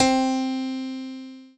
PIANO5-07.wav